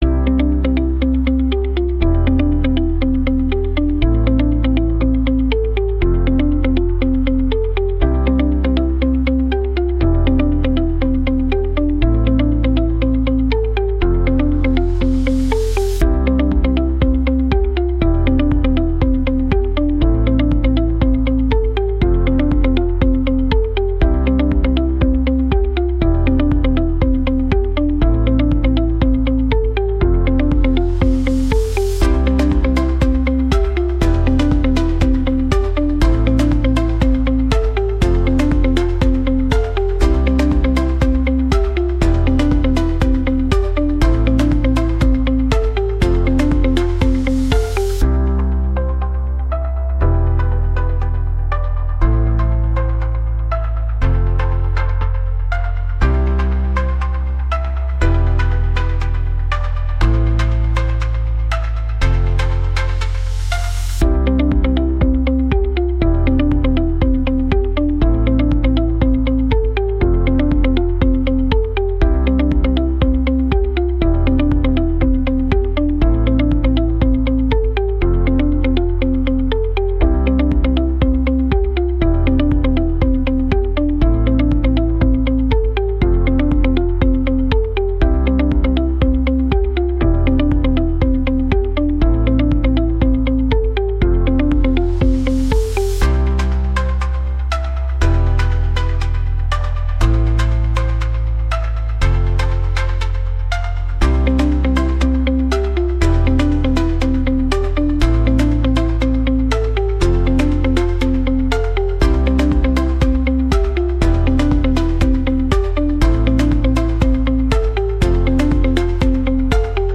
• Категория: Детские песни / Музыка детям 🎵